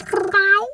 cat_2_meow_emote_04.wav